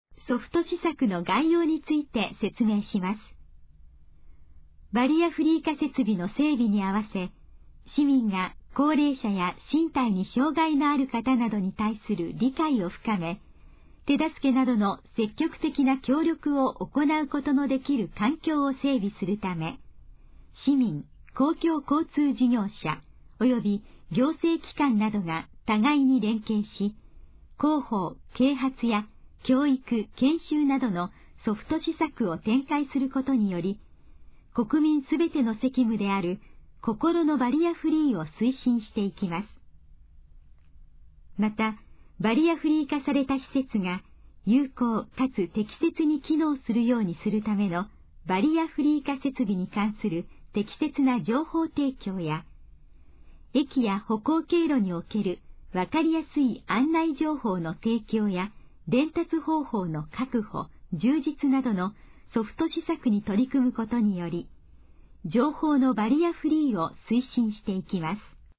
以下の項目の要約を音声で読み上げます。
ナレーション再生 約134KB